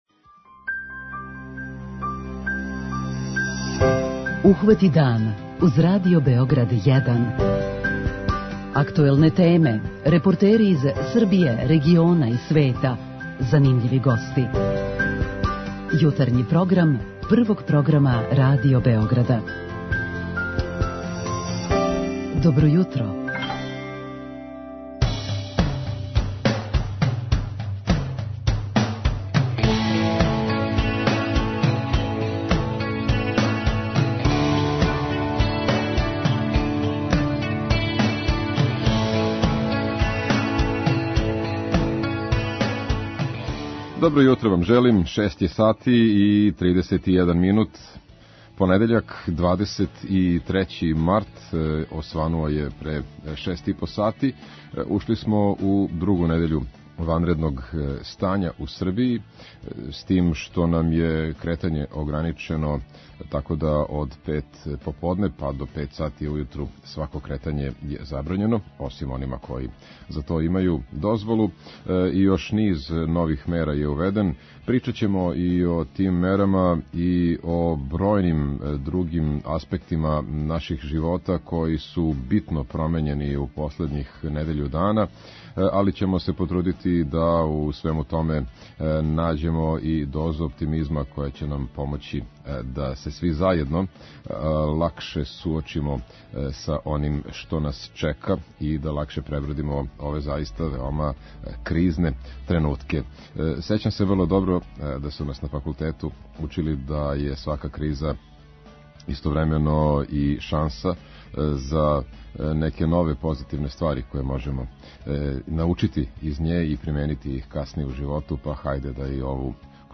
Наши репортери и дописници јављаће нам како протиче јутро у Београду и Србији, а чућемо и прилог о томе како изгледа ванредно стање на селу.